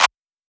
TM88 - CLAP (5).wav